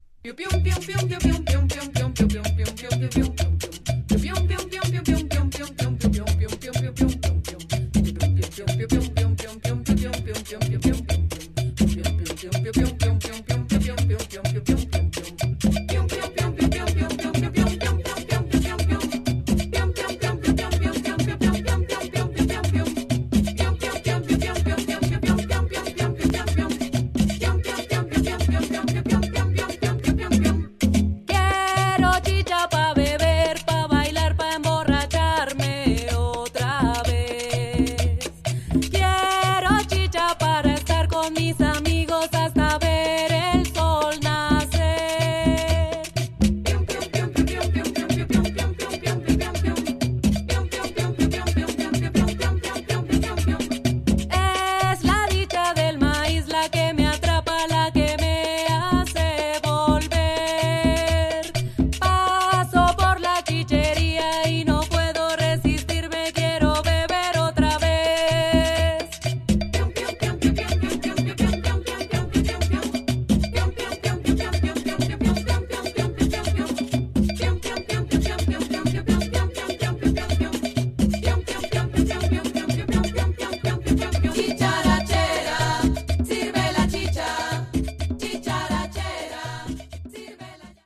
Tags: Bogotá , Japan , Folklorico